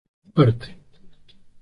Произносится как (IPA)
/ˈpaɾte/